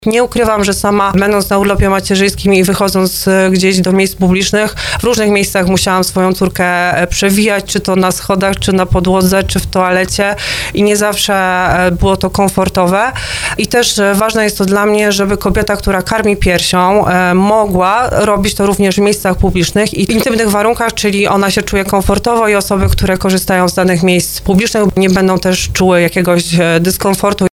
Jak podkreślała w programie Słowo za Słowo Marta Malec-Lech z zarządu województwa, dzięki temu rodziny z dziećmi będą mogły swobodniej korzystać z usług instytucji oraz uczestniczyć w życiu społecznym i kulturalnym regionu.